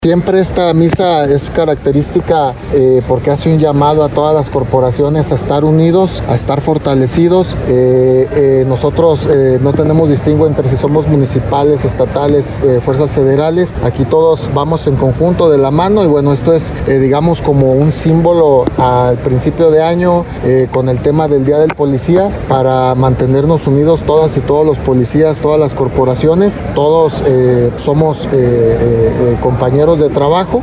En la voz de Juan Pablo Hernández, secretario de Seguridad de Jalisco.